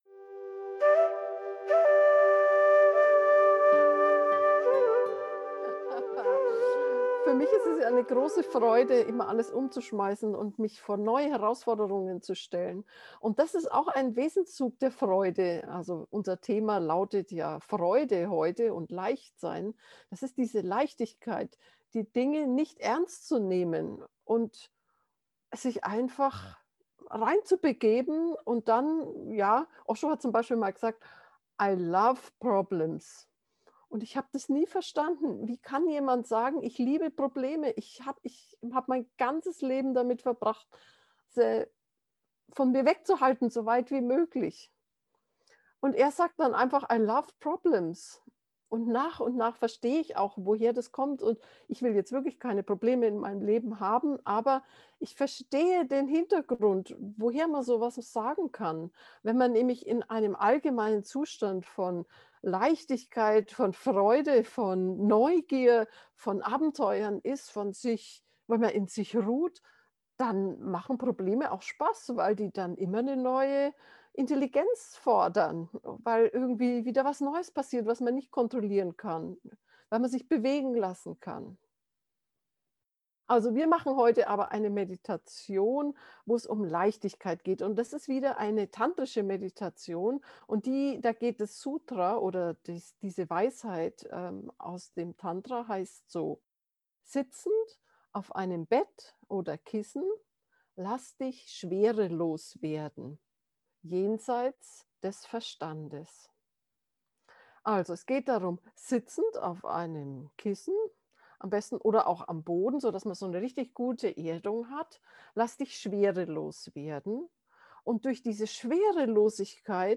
Meditationsanleitung zur geführten Meditation
freude-gefuehrte-meditation